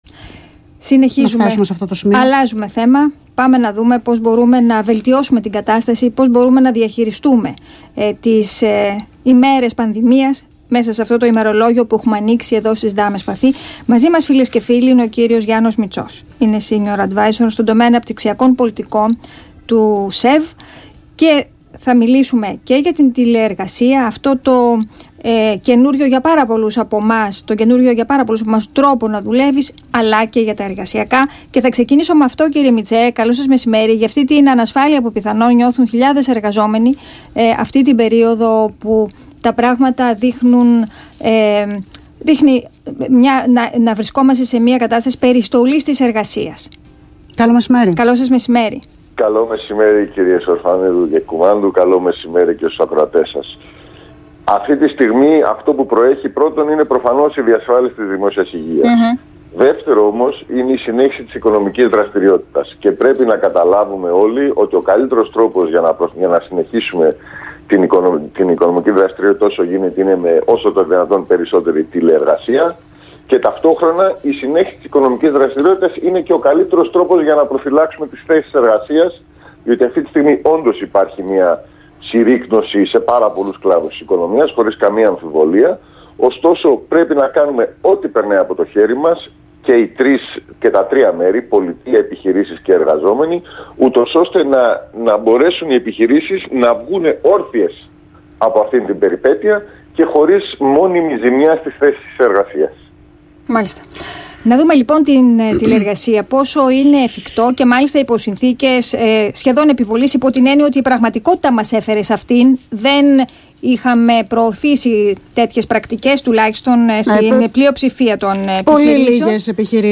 Συνέντευξη
στον Ρ/Σ ΑΘΗΝΑ 9.84